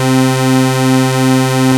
OSCAR 1  C4.wav